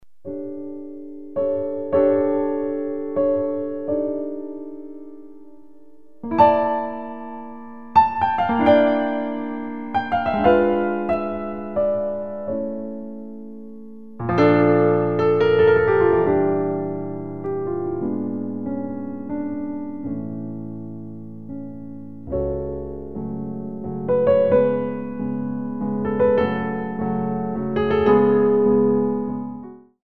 45 selections (67 minutes) of Original Piano Music